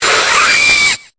Cri de Fermite dans Pokémon Épée et Bouclier.